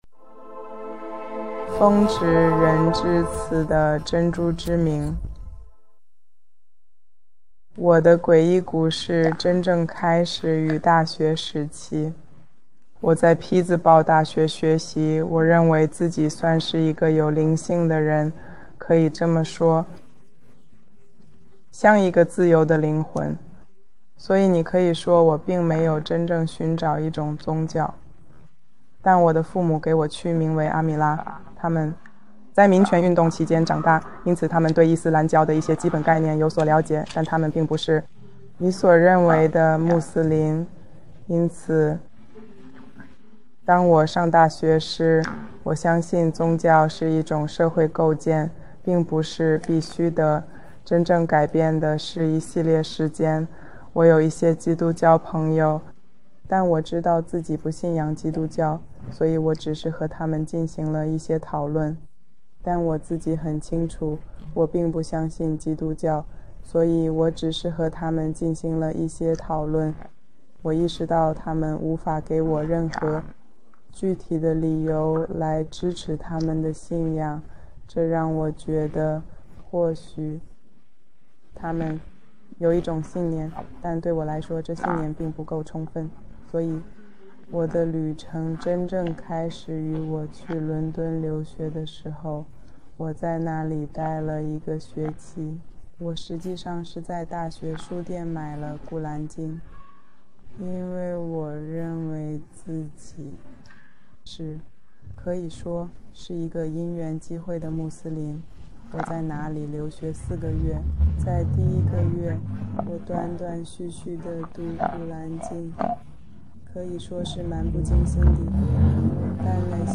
视频 新穆斯林故事 女性